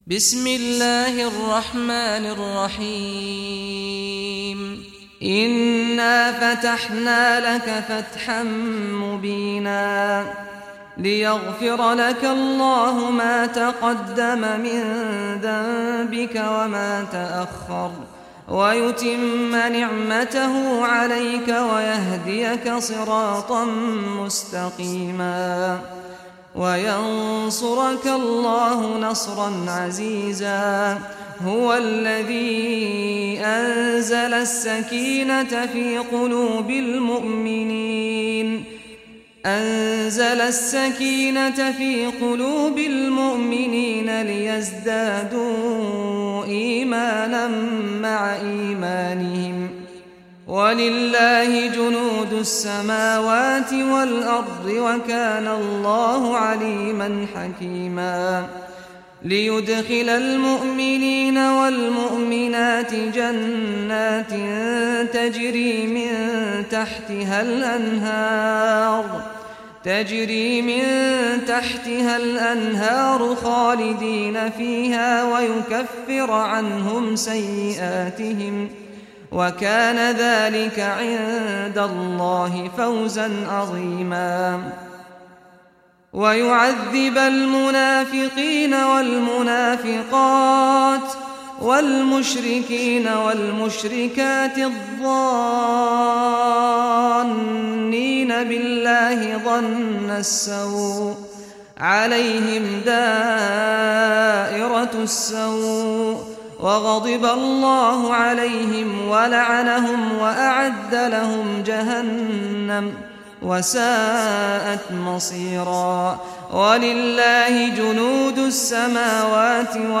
Surah al-Fath Recitation by Sheikh Saad al Ghamdi
Surah al-Fath, listen or play online mp3 tilawat / recitation in Arabic in the beautiful voice of Sheikh Saad al Ghamdi.